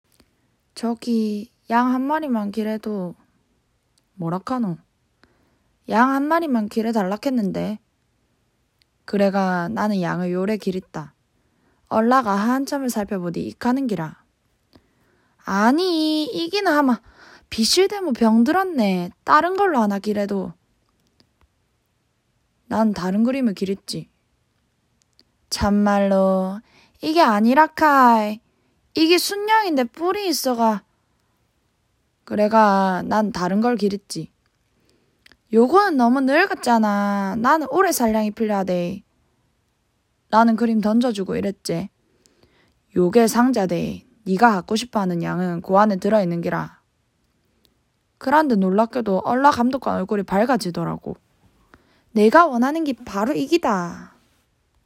독서모임을 해서 가장 사투리를 구수하게 읽은 한 멤버가 직접 낭독해주는 파일을 들어보세요!